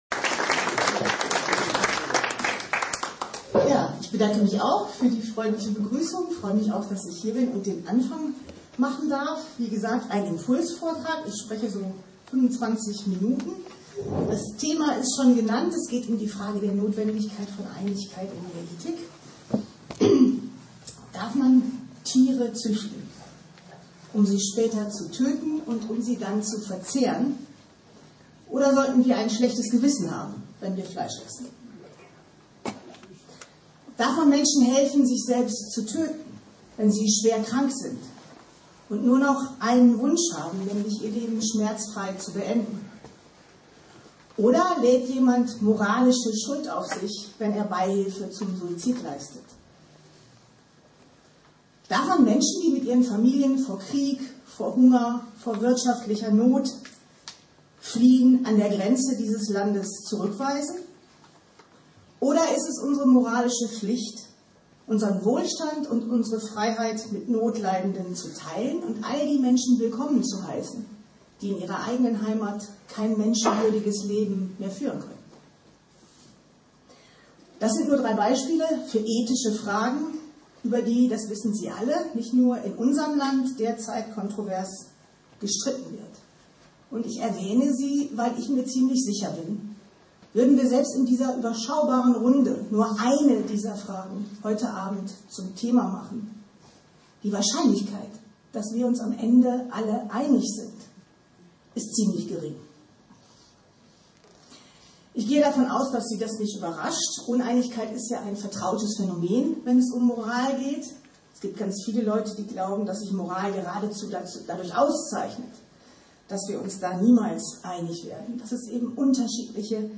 KEB-Osnabrueck_-Wieviel-Einigkeit-braucht-die-Ethik_-Vortrag-und-Podiumsdiskussion.mp3